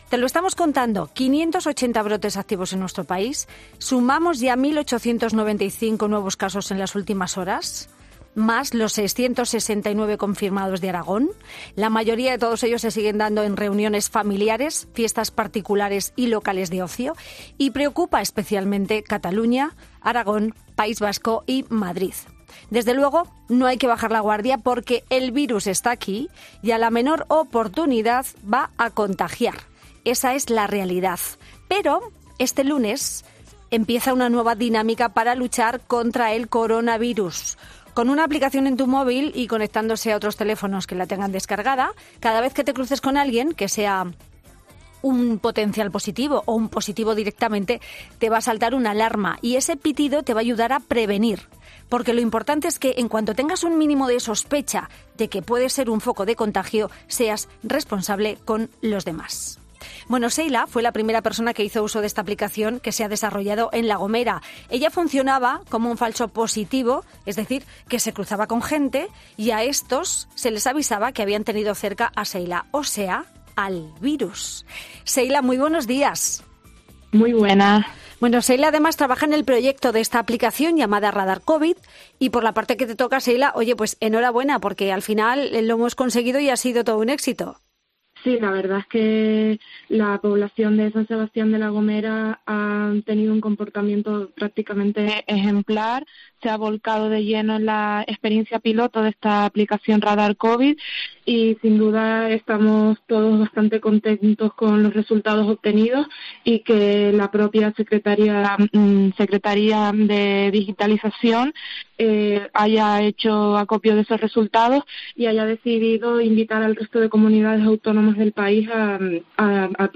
Fin de Semana habla con sus principales responsables y un experto...